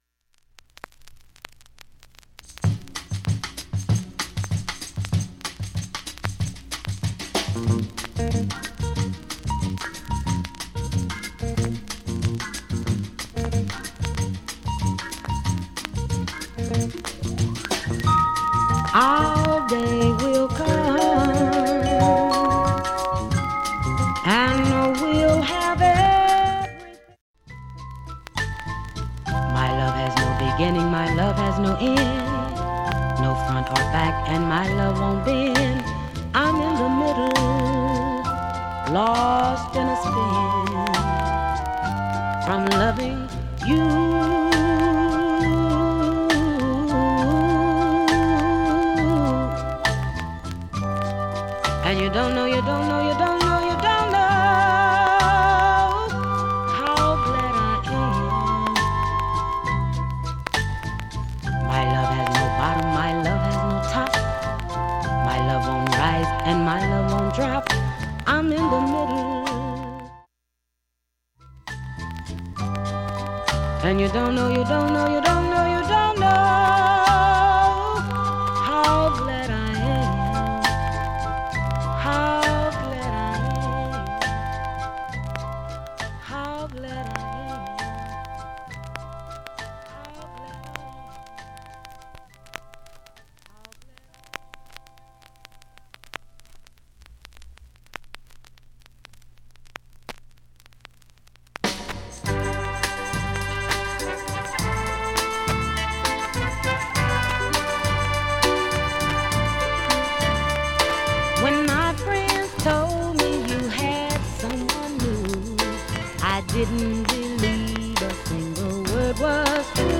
細かい傷等で、プツは頻繁に出ますが、
ひどいジりパチはありません。
静かな部でかすかなチリ程度。
ダイジェスト試聴、主にプツ出ている個所
10回までのかすかなプツが４箇所